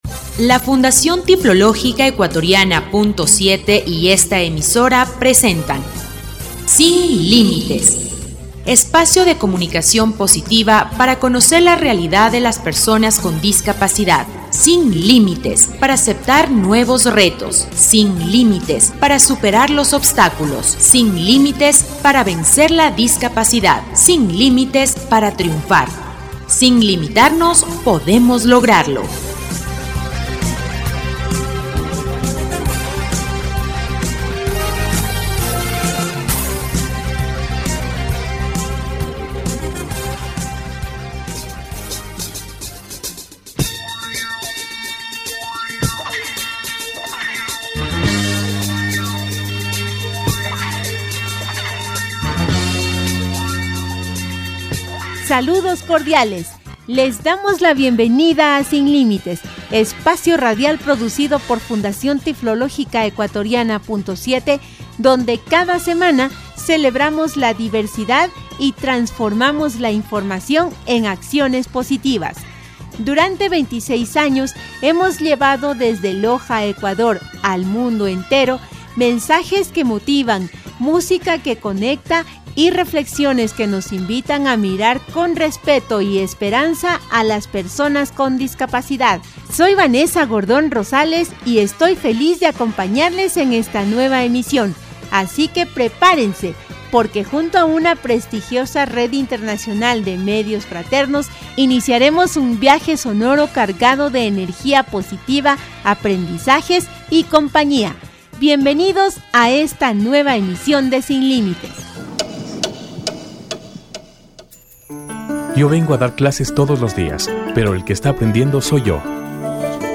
Espacio de comunicación positiva para conocer la realidad de las personas con discapacidad, disfruta de una nueva edición del programa radial «Sin Límites».